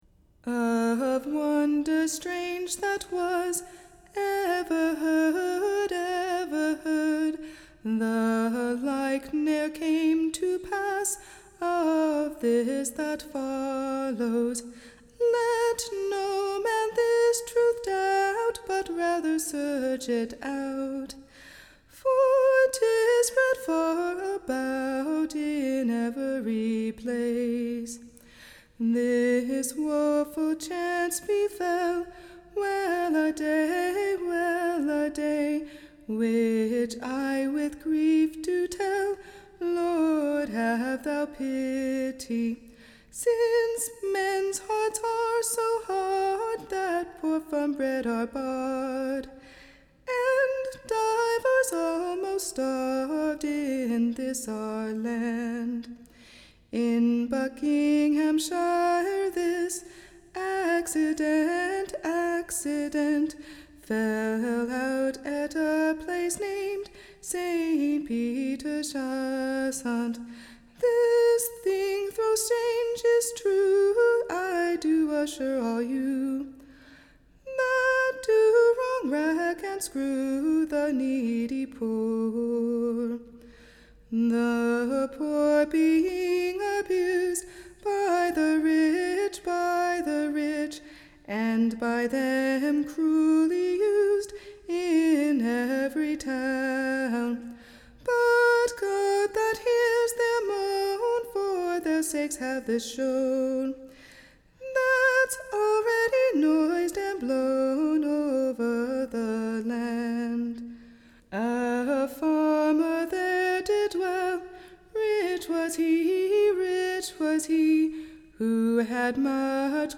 Recording Information Ballad Title A Looking glasse for Corne-hoorders, / By the example of Iohn Russell a Farmer dwelling at St Peters / Chassant in Buckingham shire, whose Horses sunke into the / ground the 4 of March 1631. Tune Imprint To the tune of Welladay.